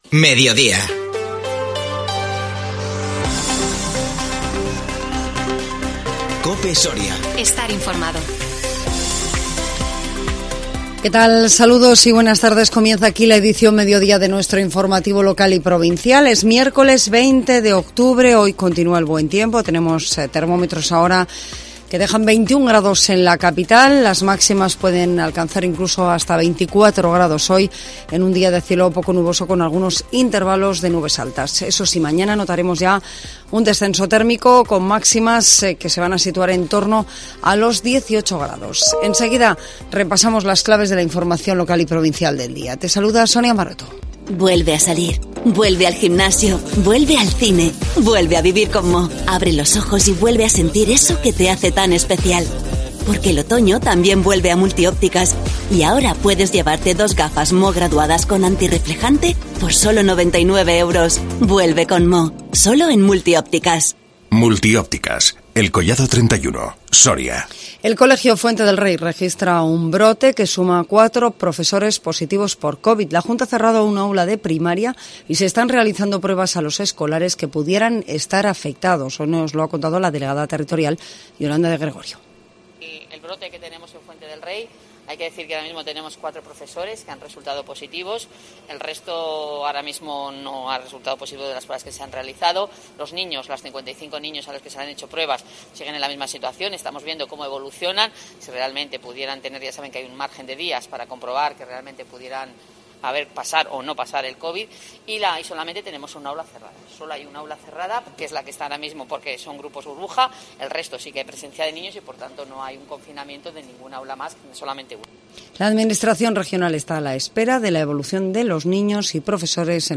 INFORMATIVO MEDIODÍA 20 OCTUBRE 2021